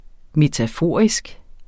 Udtale [ metaˈfoˀɐ̯isg ]